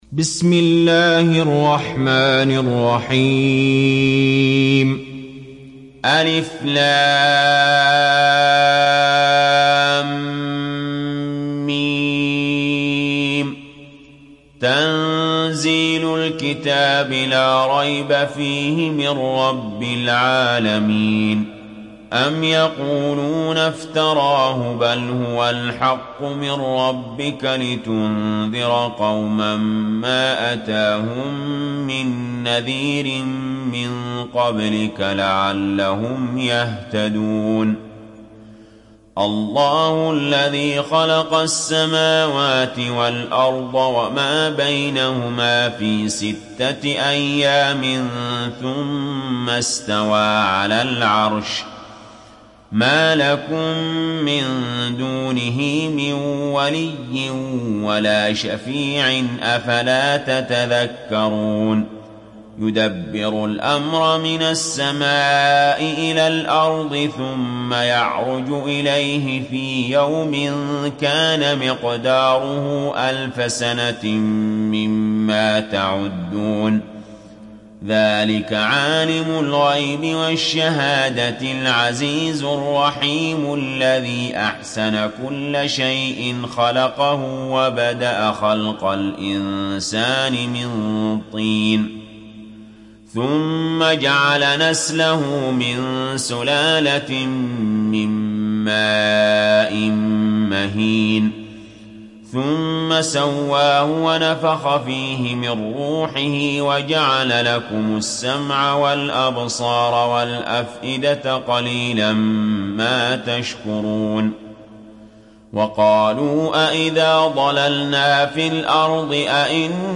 تحميل سورة السجدة mp3 بصوت علي جابر برواية حفص عن عاصم, تحميل استماع القرآن الكريم على الجوال mp3 كاملا بروابط مباشرة وسريعة